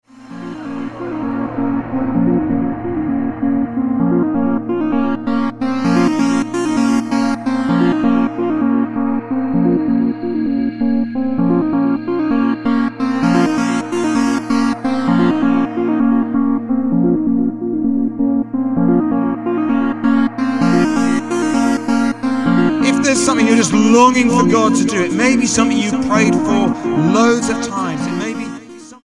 fuses preacher samples to dance music
Style: Dance/Electronic